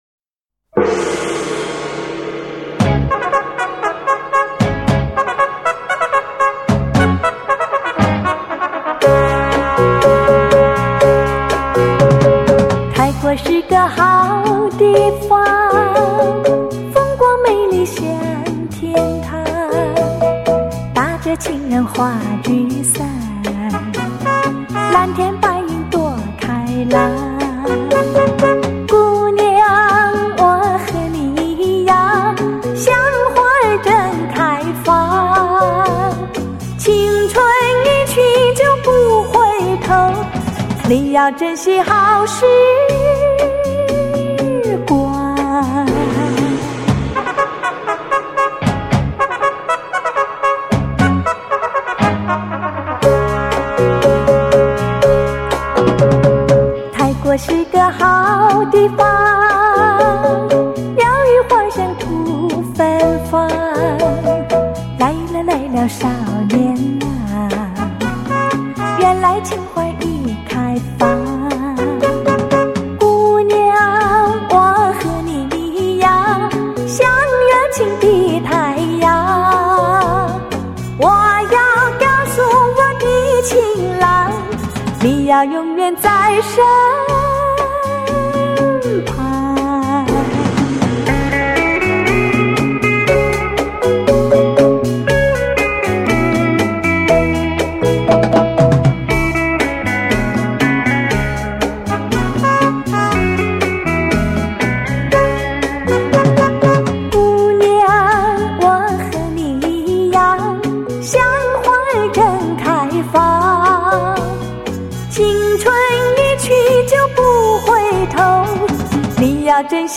聆觅丽影君情歌华倩韵 黑胶唱片原音回放
不带粉饰的清音雅韵 纯真雅洁的朴素情怀
既保留了黑胶唱片的暖和柔美 也展现了数码唱片的精确清晰
让那甜蜜柔美的声音再度飘进你的心窝 萦牵你的心弦